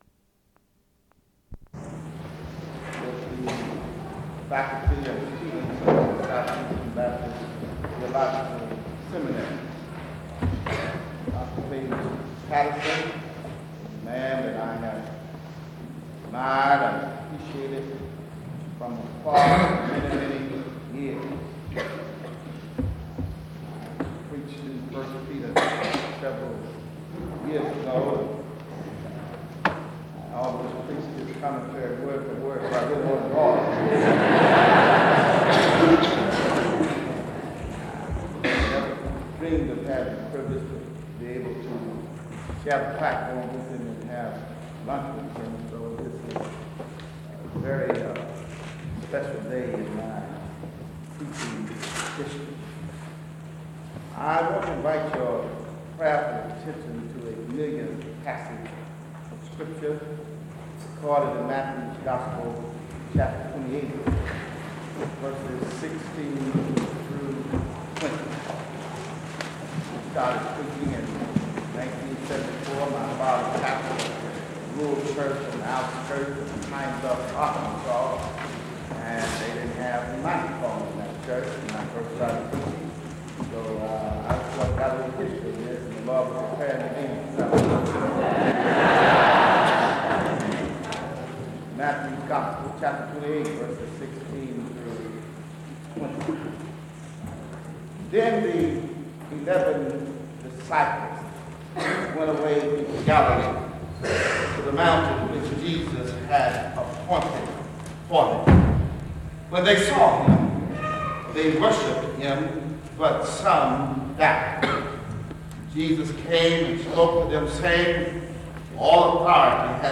Location Wake Forest (N.C.)
SEBTS Chapel and Special Event Recordings - 2000s